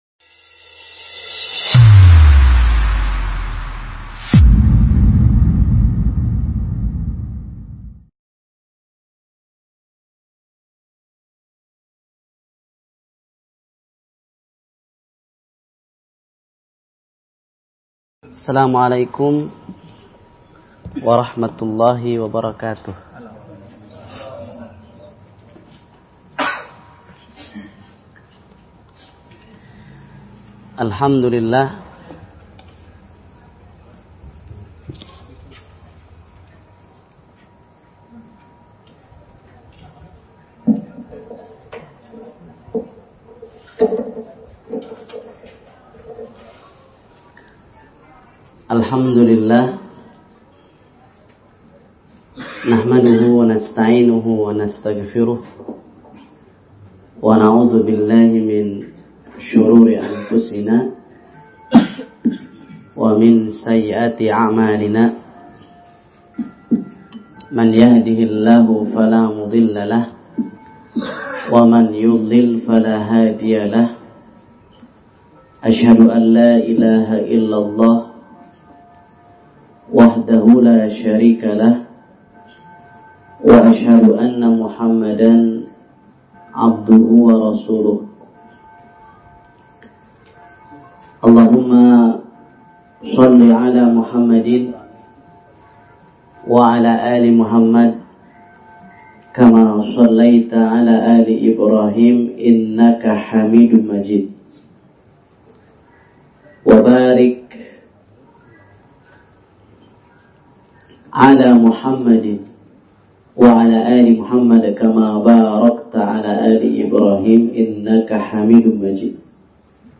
Sesi tanya jawab membahas berbagai permasalahan penting.